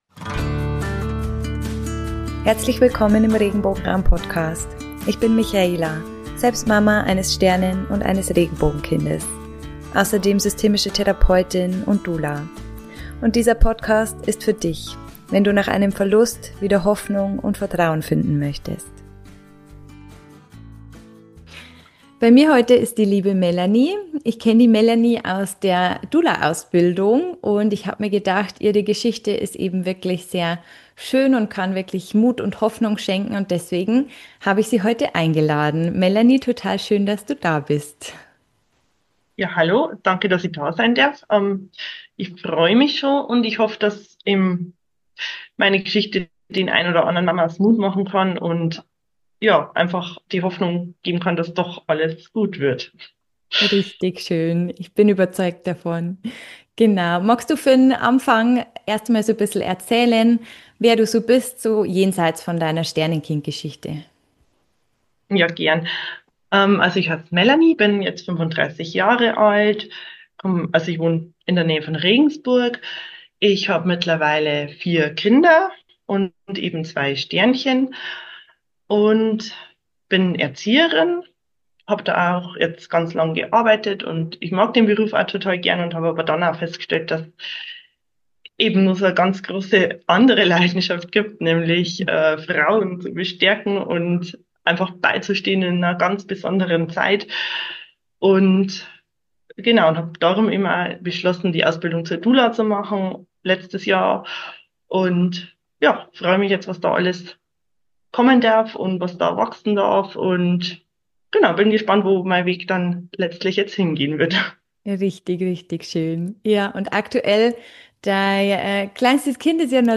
In dieser Folge spreche ich mit meiner Doula Kollegin über ihren ganz persönlichen Weg durch Verlust, Vertrauen und erneutes Mutterwerden.